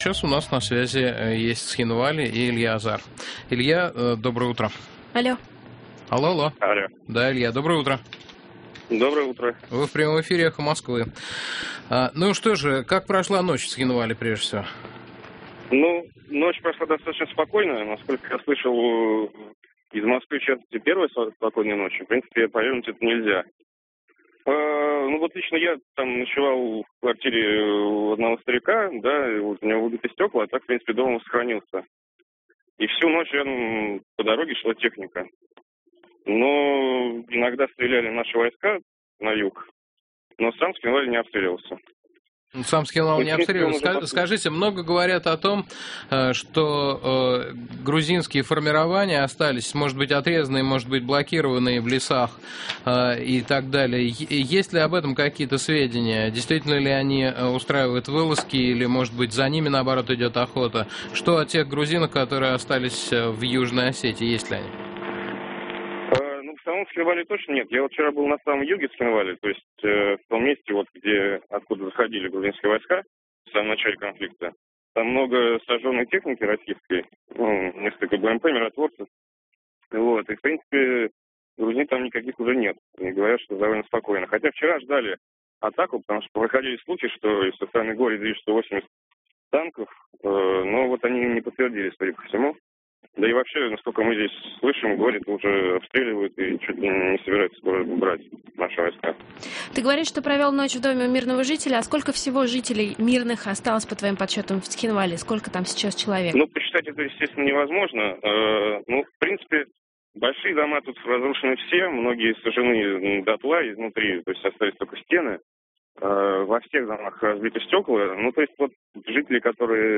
Репортаж из Цхинвали - Илья Азар - Разворот (утренний) - 2008-08-12